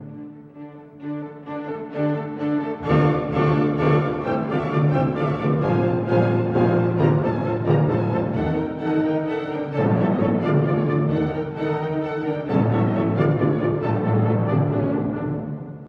↑古い録音のため聴きづらいかもしれません！（以下同様）
「フリアント」と言われる農民の激しい踊りです。
楽譜上は全て3拍子で書かれていますが、実際には「2拍子×3」の後に「3拍子×2」が続きます。
特にティンパニが強烈で、この2拍子／3拍子の要素をこれでもかと言わんばかりに叩き続けます。
この変則的なリズムと急速なテンポがあいまって、力強く推進力があり、かつ激しい曲想を聴く者に印象付けます。